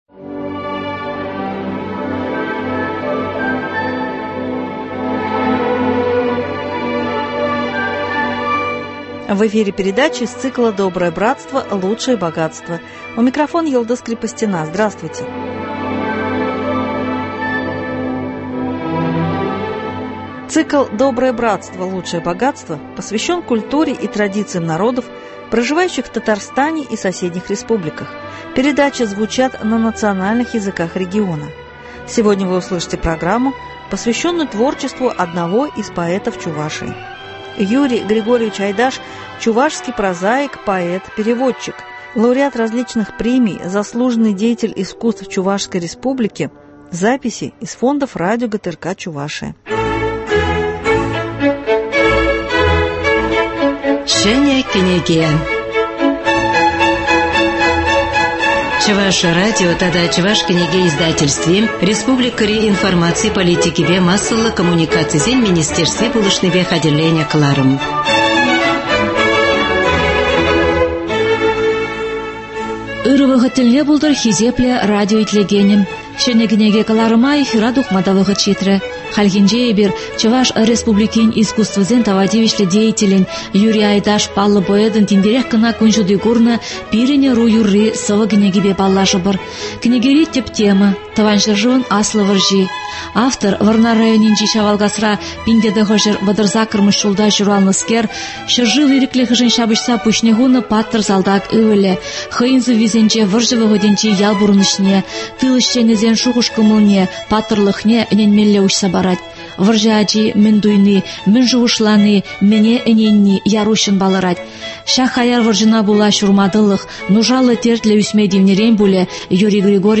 Лауреат различных премий, заслуженный деятель искусств Чувашской Республики .Записи из фондов радио ГТРК Чувашия.